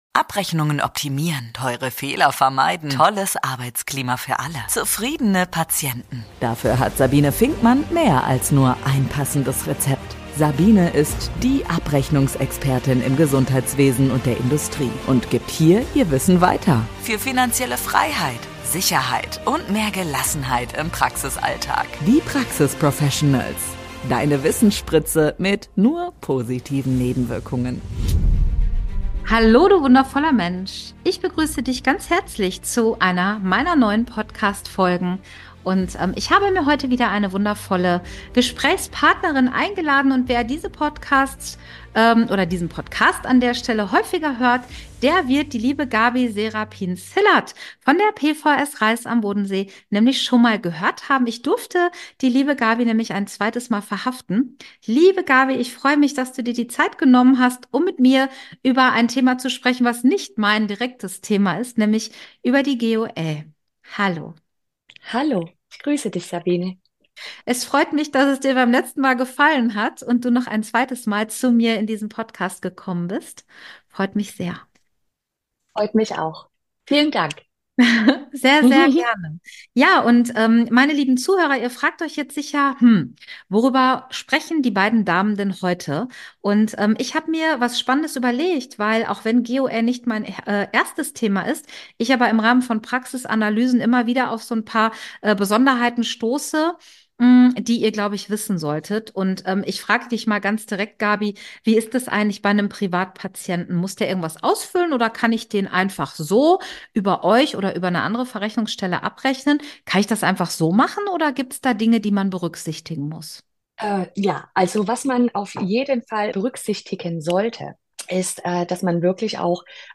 Und darf eine Mitarbeiterin einen Kostenvoranschlag zu IGEL Leistungen unterschreiben? Diese und weitere Fallstricke besprechen beide in dieser Podcastfolge.